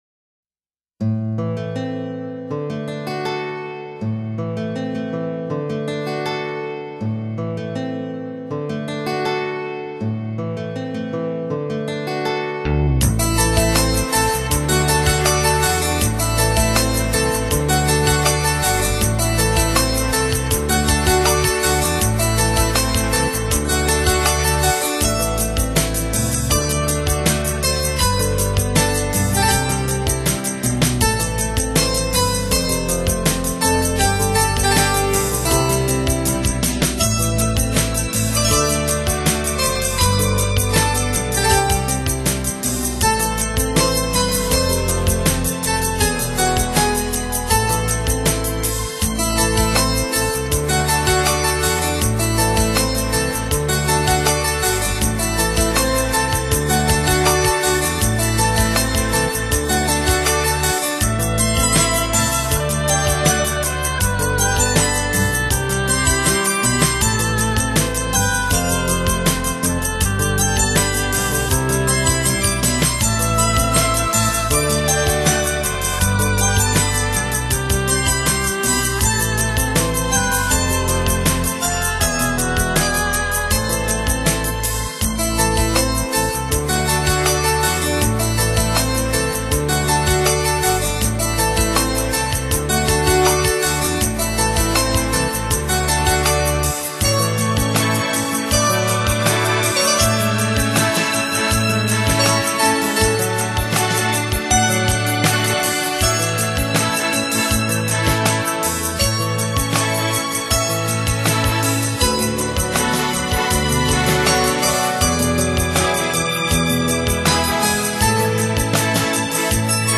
創新樂風，融合古典與現代的樂器與演奏，所以他的音樂被列在 New Age的型